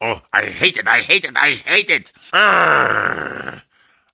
Wario_(Lose_3_alt)_Mario_Kart_Wii.oga